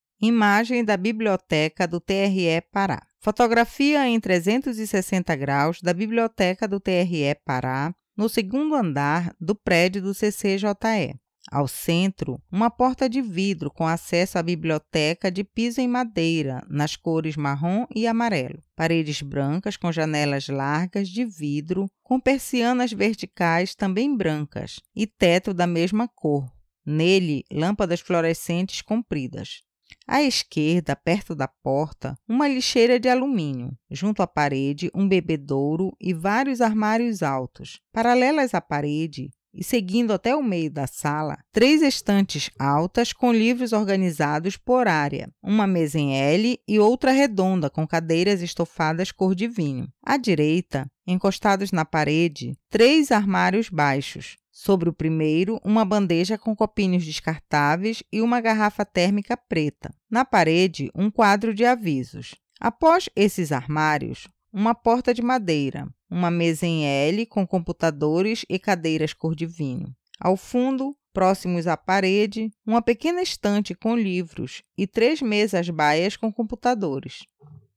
Biblioteca do TRE Pará audiodescrição